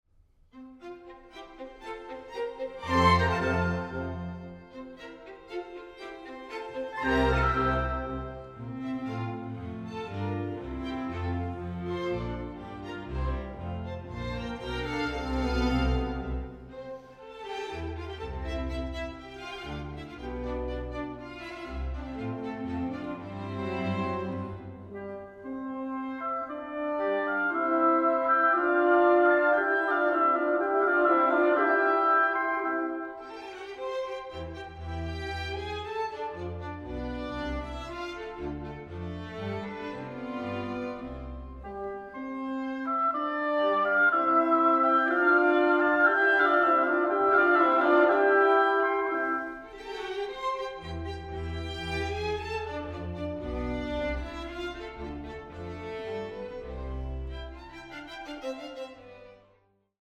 Andantino grazioso